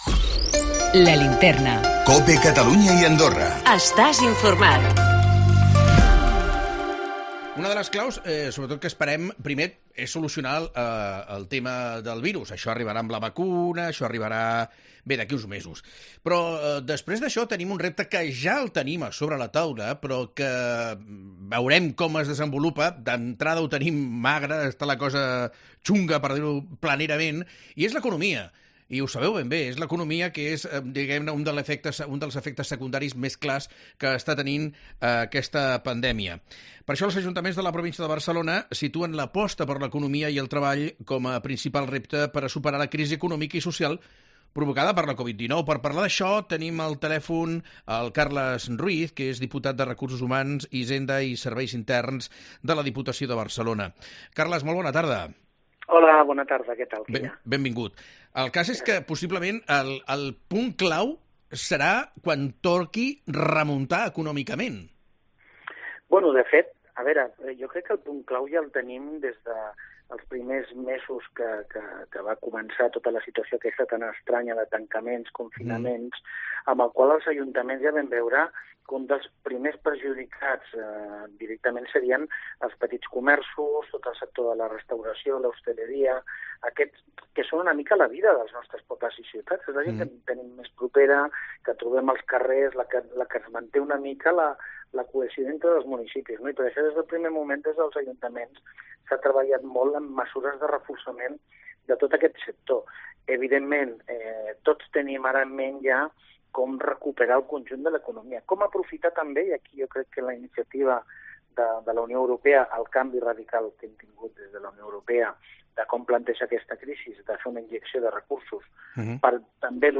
A La Linterna Catalunya hem parlat amb el Carles Ruiz, diputat de recursos humans, hisenda i serveis interns de la Diputació de Barcelona "ajudar al comerç local és essencial, ells són el teixit fonamental de totes les nostres poblacions" explica el diputat "els hem d'ajudar econòmicament i fer un esforç per acabar de digitalitzar tot aquest teixit per fer-ho més competitiu devant les mega grans empreses"